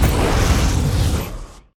flamethrower.ogg